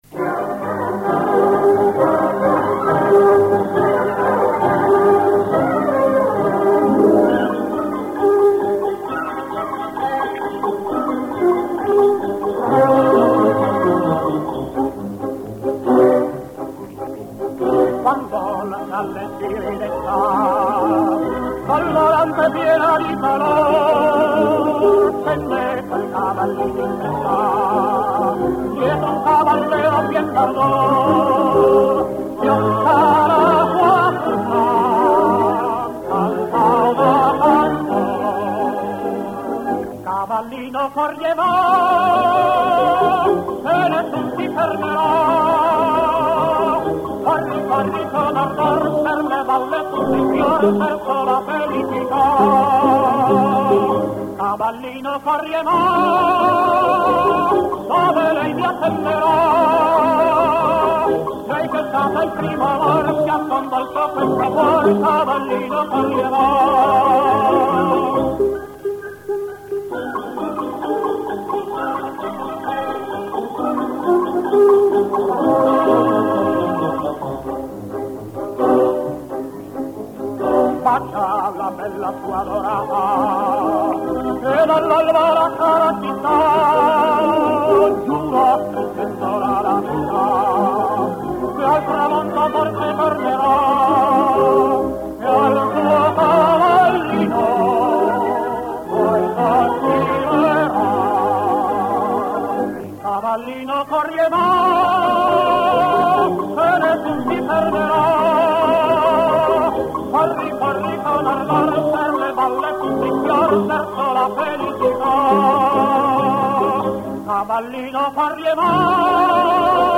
А песня оказалась 1940-42года.